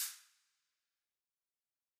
hat.ogg